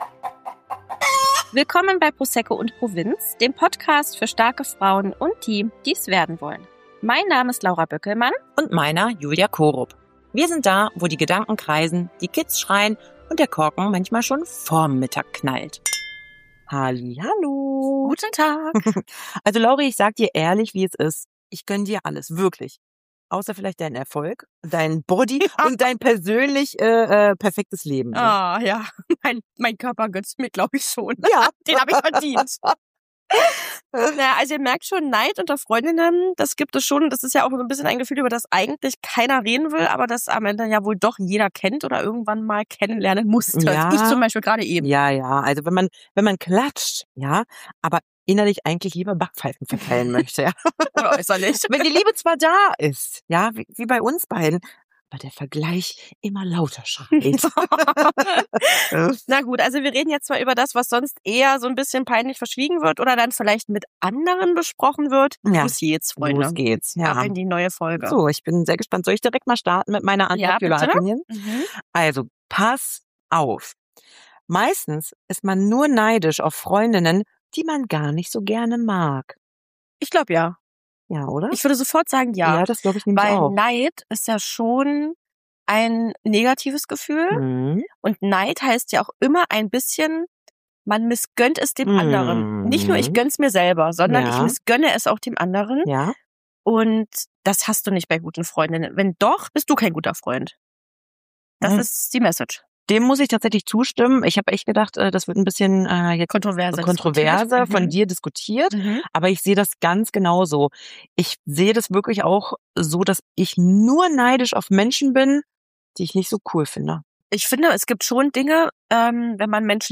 Diese Folge ist so ehrlich, dass wir mehr Namen piepen mussten als ein Reality-Format um 23:45 Uhr. Wir reden über Neid.